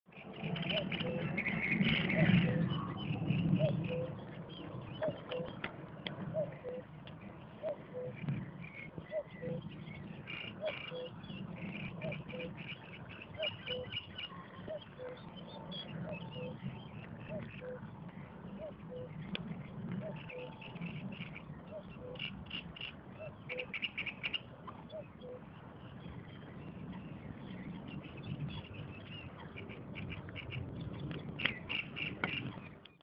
NSG Karower Teiche
(zu finden westlich der S2 zwischen den haltestellen karow und buch)